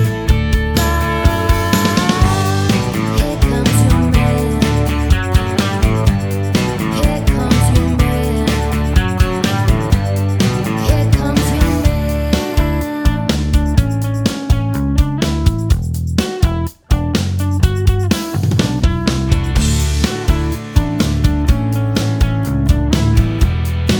no Backing Vocals Indie / Alternative 3:23 Buy £1.50